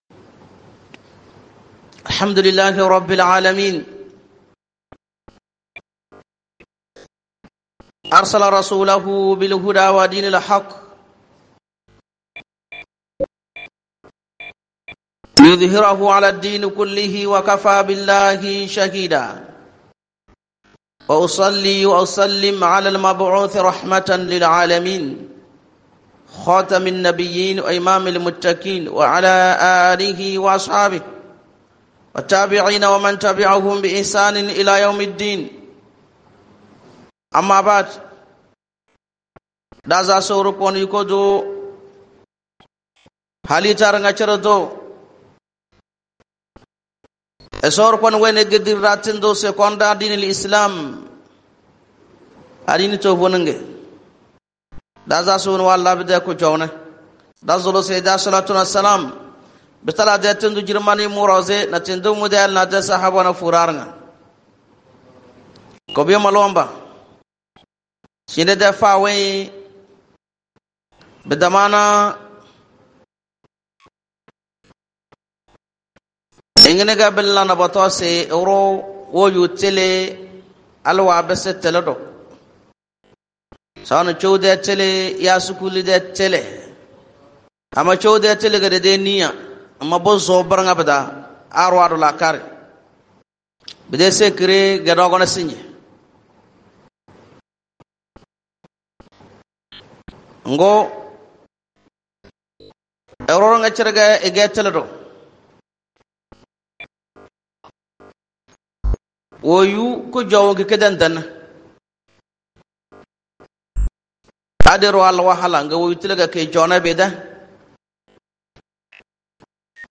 LA QUETTE DU SAVOIR ISLAMIQUE PRECHE LA QUETE DU SAVOIR ISLAMIQUE 18.09.2024 Télécharger Laisser un commentaire Annuler la réponse Votre adresse e-mail ne sera pas publiée.